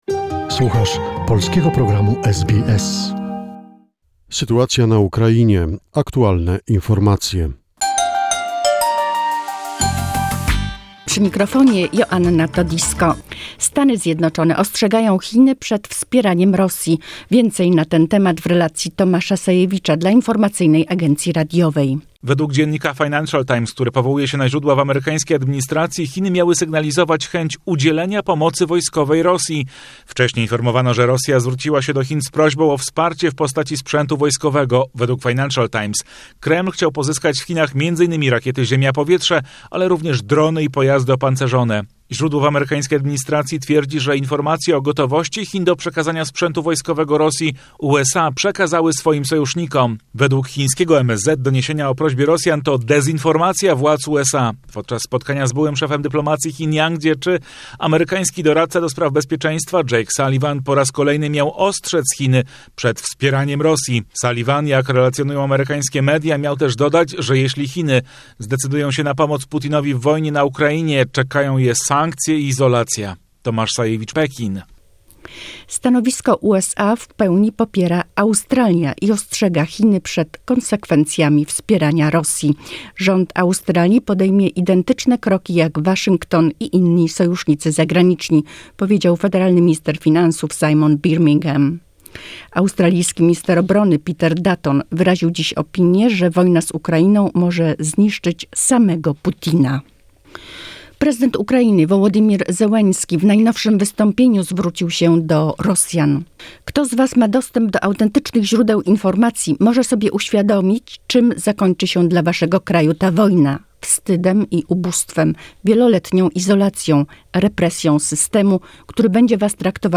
The most recent information and events about the situation in Ukraine, a short report prepared by SBS Polish.